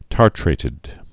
(tärtrātĭd)